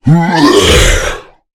spawners_mobs_balrog_death.1.ogg